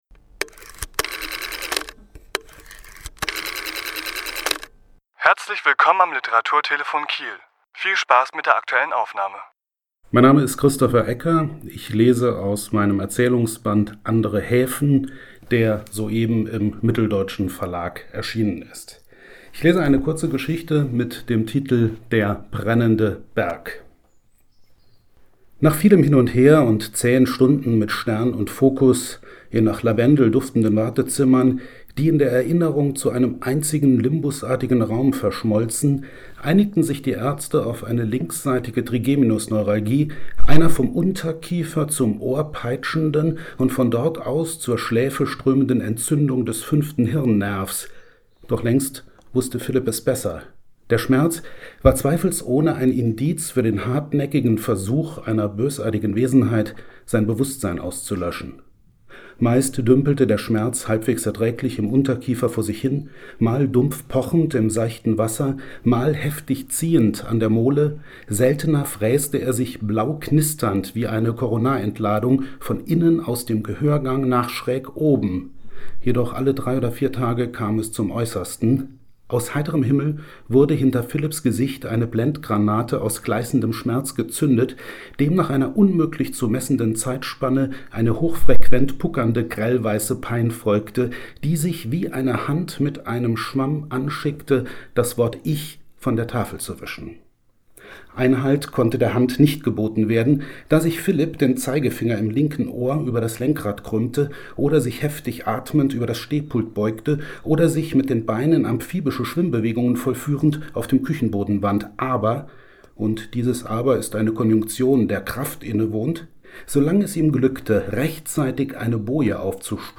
Autor*innen lesen aus ihren Werken
Die Aufnahme entstand anlässlich einer Lesung im Literaturhaus Schleswig-Holstein am 26.9.2017.